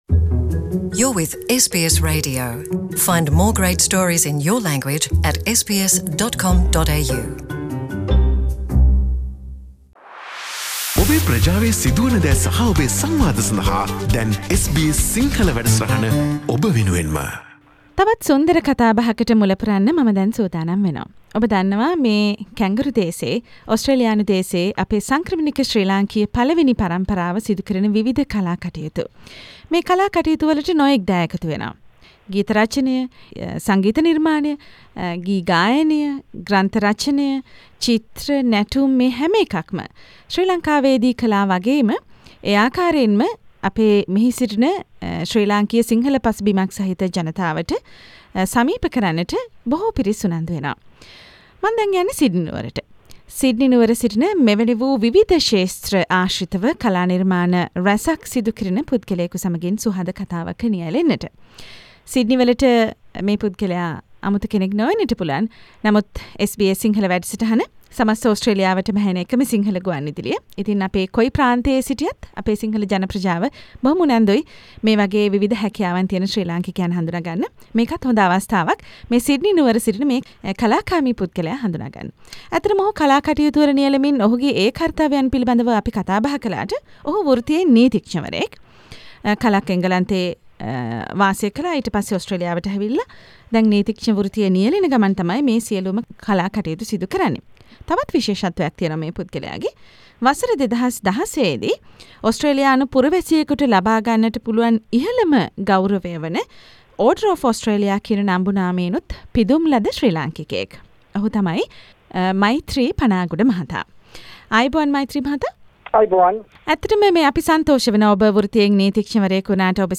SBS සිංහල ගුවන්විදුලිය සමඟින් සිදුකල රසබර සාකච්චාවක්